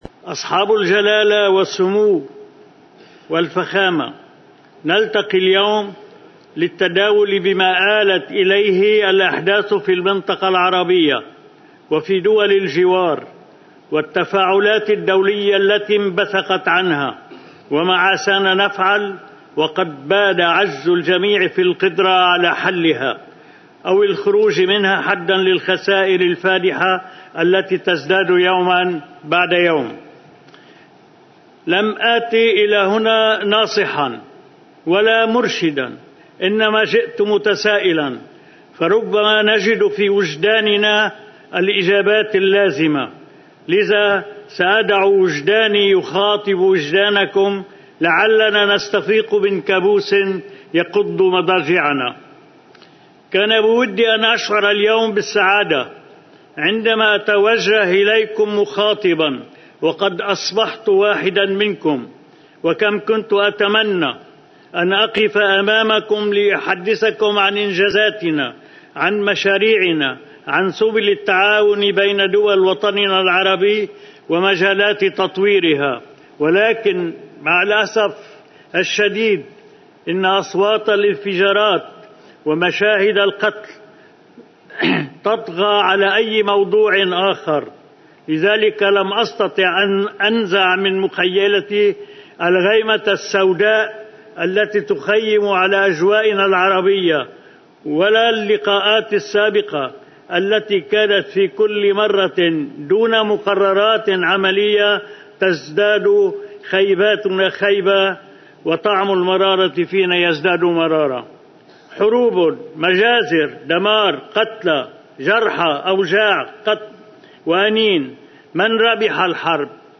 كلمة رئيس الجمهورية ميشال عون في القمة العربية في الأردن:
Aoun-Jordan-Arab.mp3